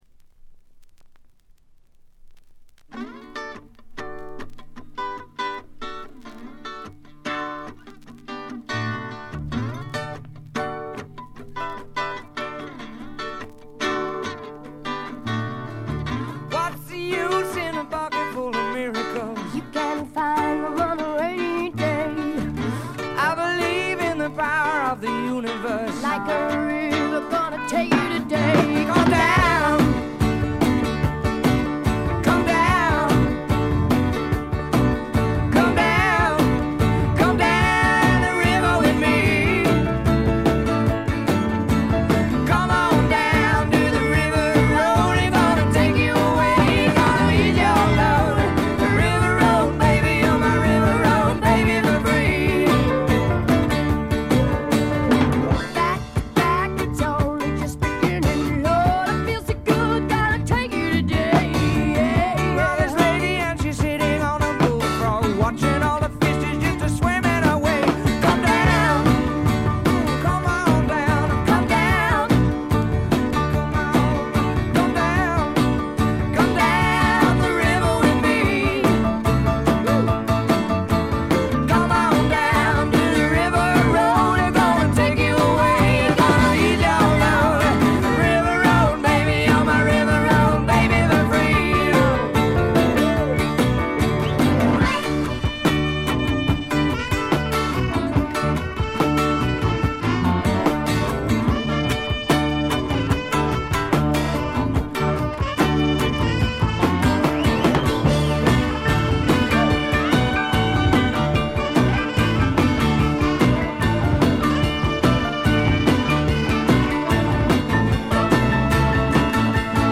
部分試聴ですが軽微なチリプチと散発的なプツ音が少し出る程度。
試聴曲は現品からの取り込み音源です。